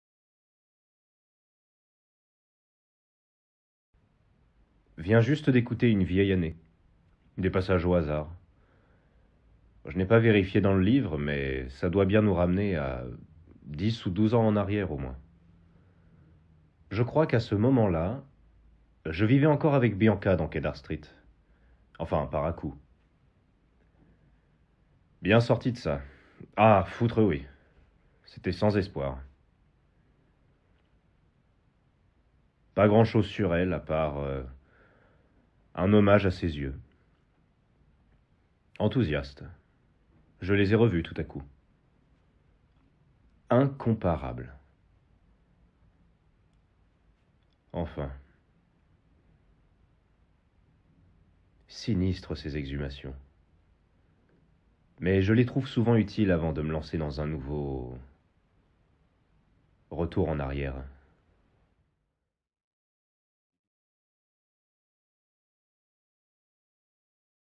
Bande son (extrait de la dernière bande)
16 - 30 ans - Basse